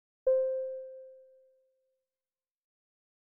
bell
bell ding ring sound effect free sound royalty free Sound Effects